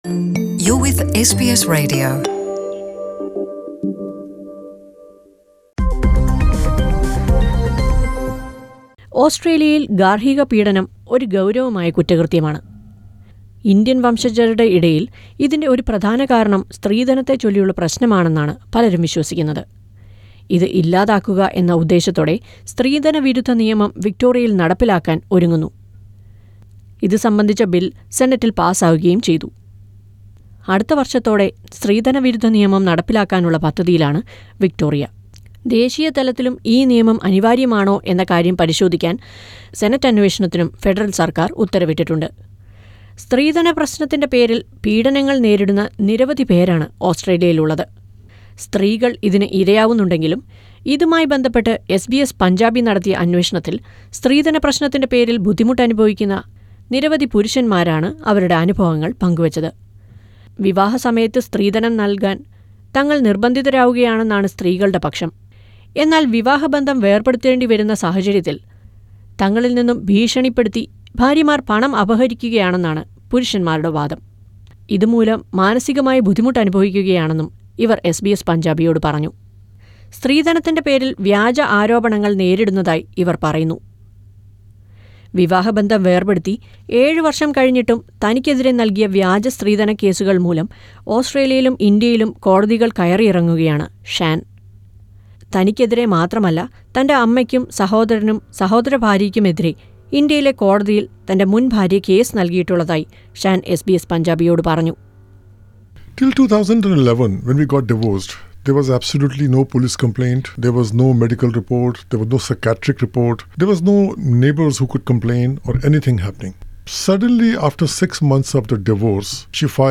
While the cultural practice of dowry is linked to domestic violence against Indian women living in Australia, SBS Punjabi has uncovered a number of false cases of dowry abuse lodged against Indian men living in Australia. Listen to a report on that...